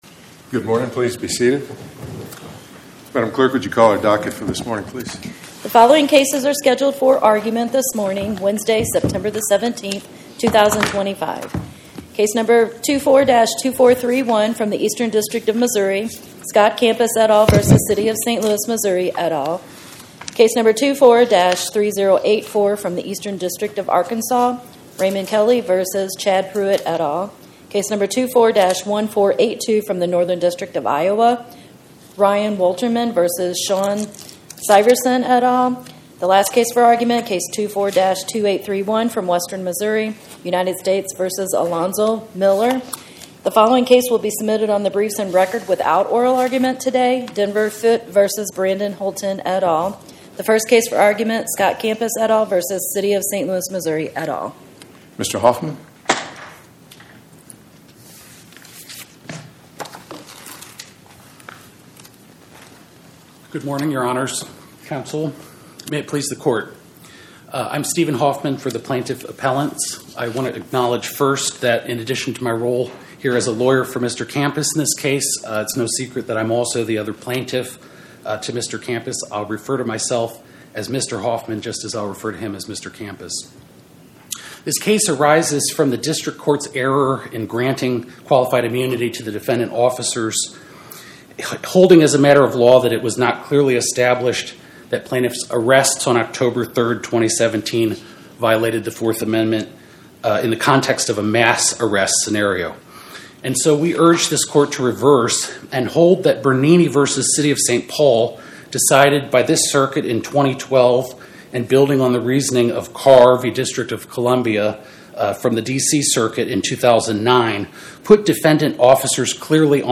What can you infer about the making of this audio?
Oral argument argued before the Eighth Circuit U.S. Court of Appeals on or about 09/17/2025